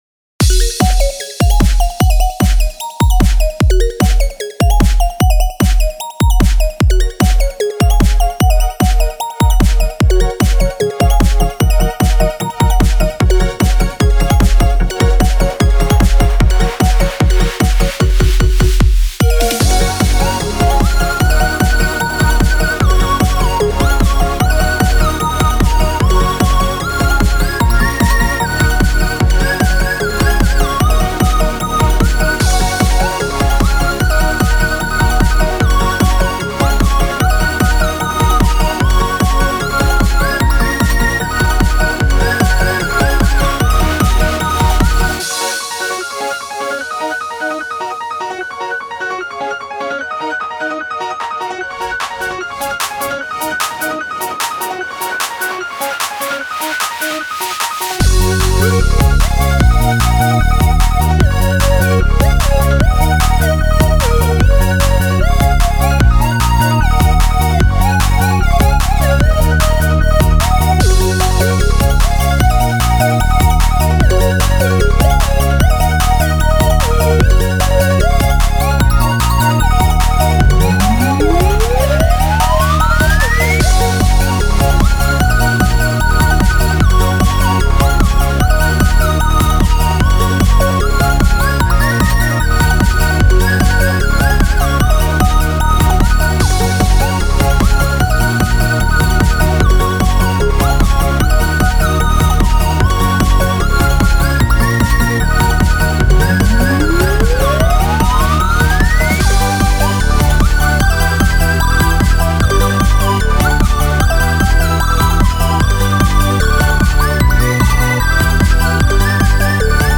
少し遅めのDnBです。
夏をイメージした曲です。